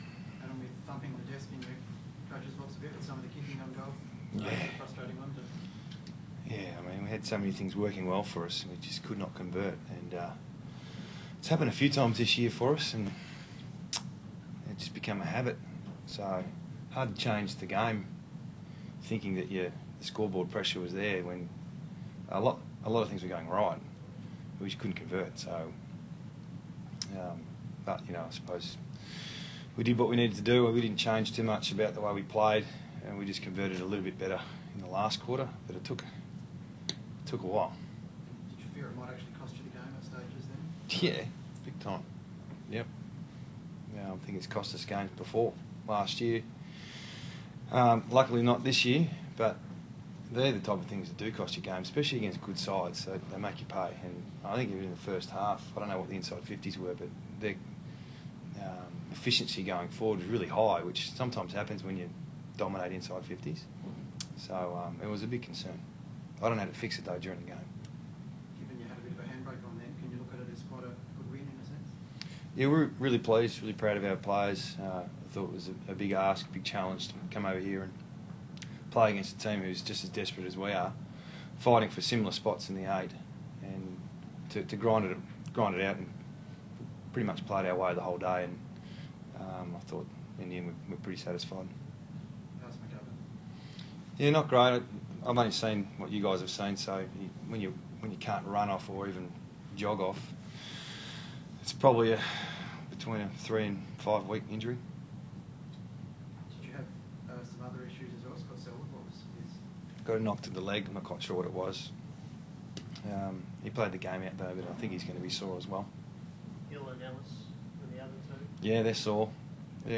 Adam Simpson Post Game Press Conference
Adam Simpson West Coast Eagles Coach